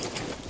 Gear Rustle Redone
tac_gear_28.ogg